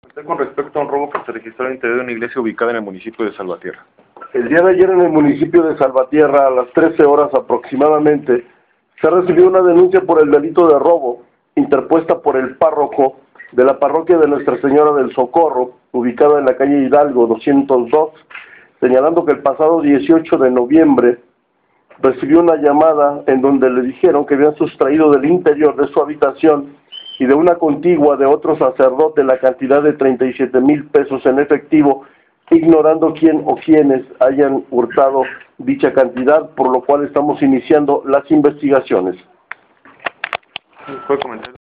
AUDIO del Subprocurador de Justicia Región C, Armando Amaro Vallejo al respecto de robo en iglesia: